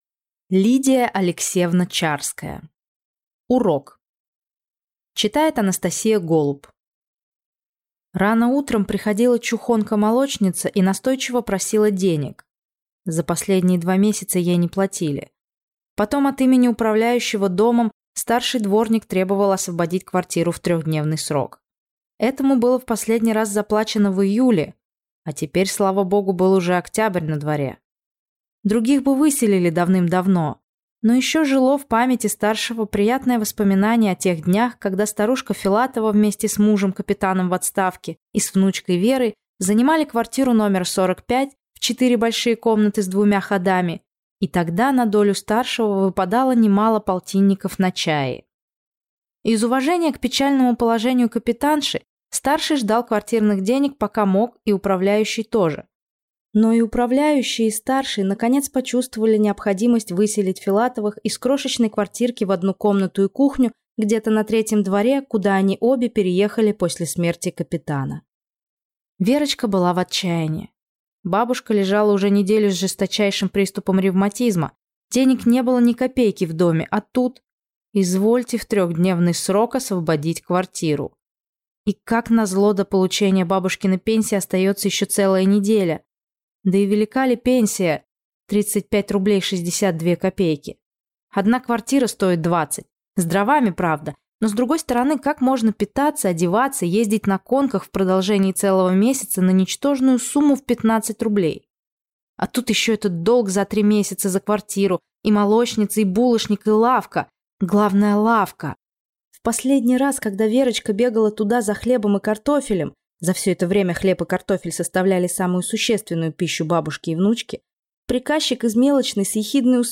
Аудиокнига Урок | Библиотека аудиокниг